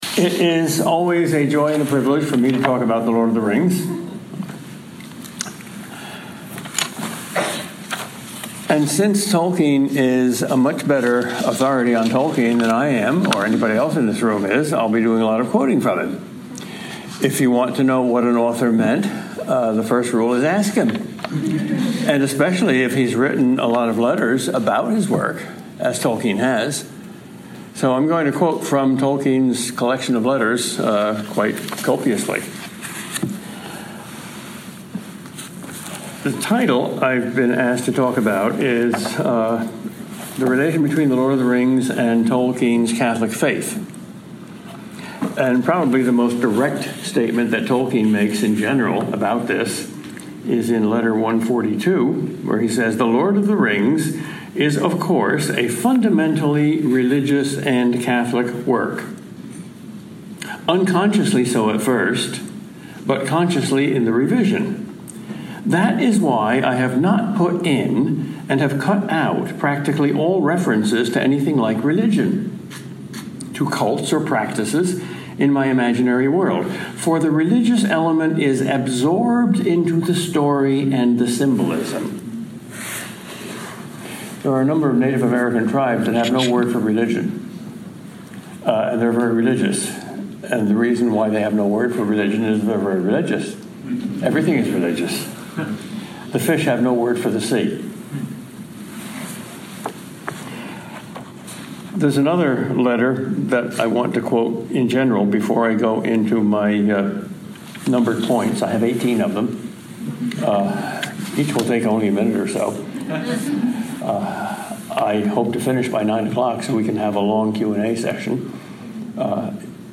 This lecture was given by Prof. Peter Kreeft (Boston College) at Rutgers University on 8 October 2019.